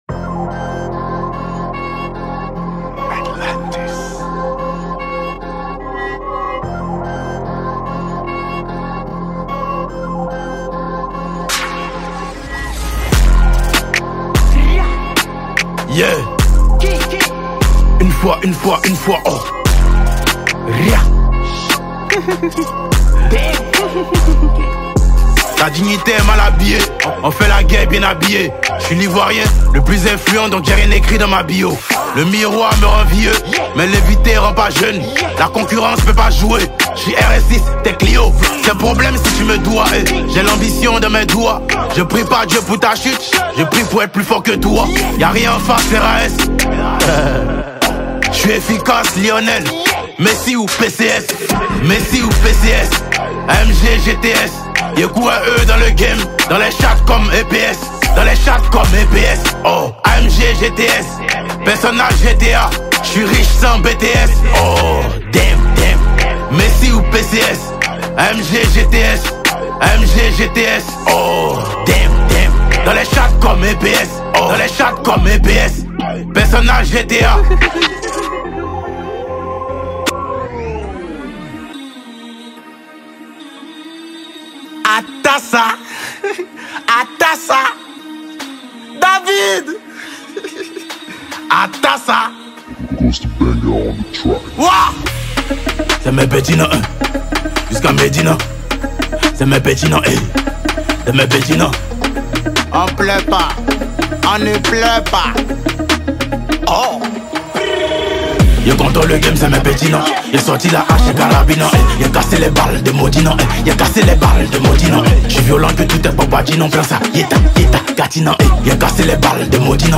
| Rap ivoire